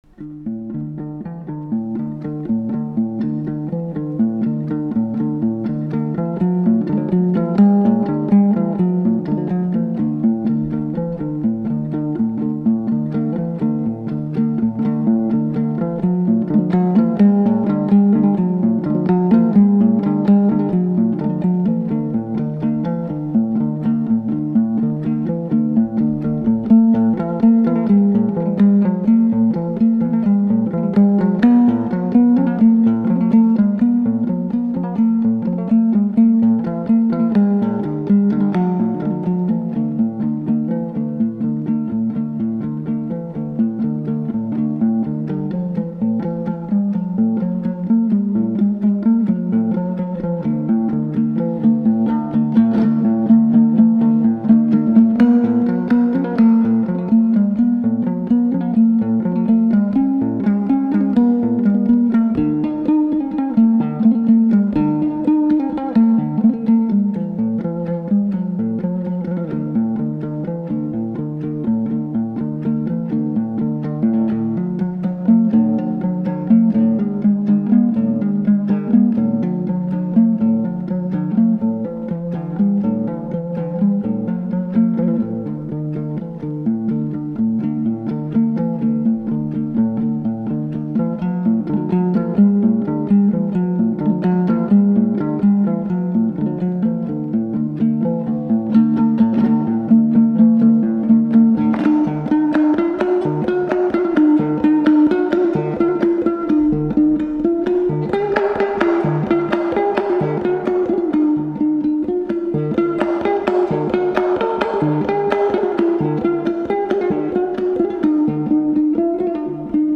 относящаяся к жанру поп-фолк.